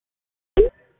Identify Dialers By Call Answer Sound
(VICIdial) commonly heard when dealing with most scam calls